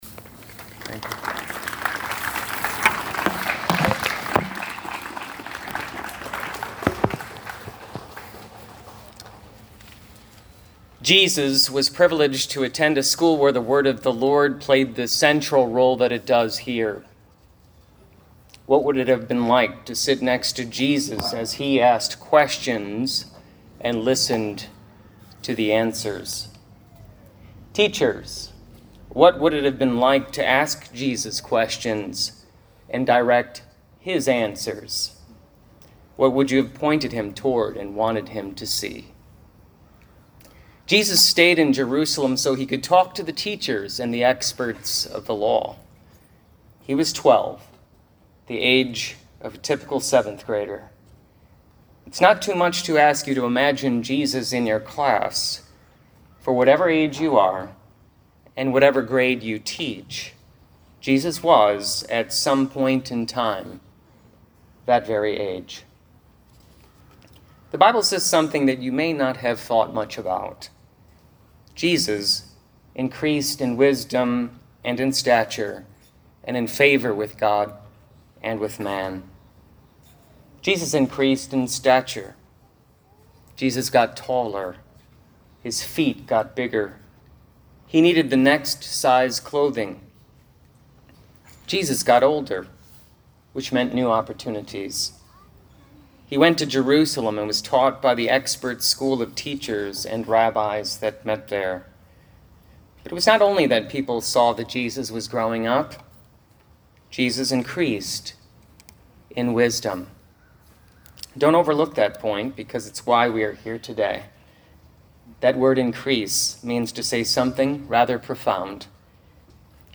2021 Convocation Address.mp3